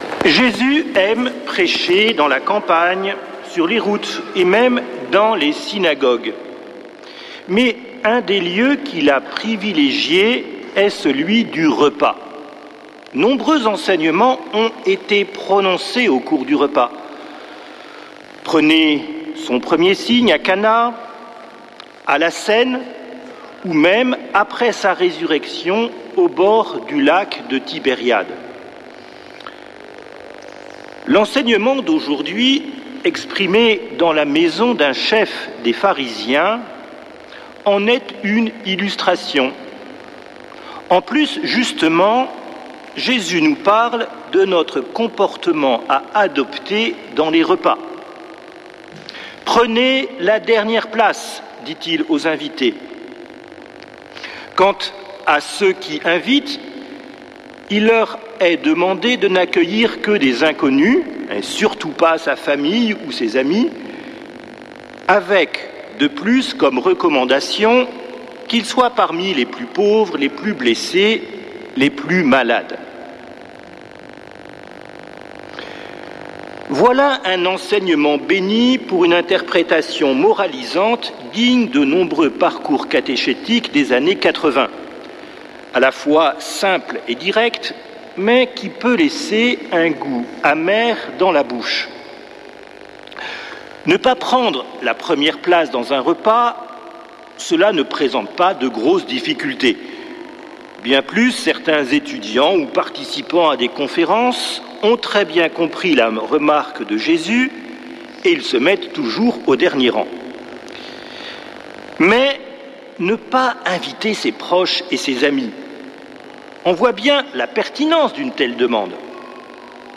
dimanche 31 août 2025 Messe depuis le couvent des Dominicains de Toulouse Durée 01 h 28 min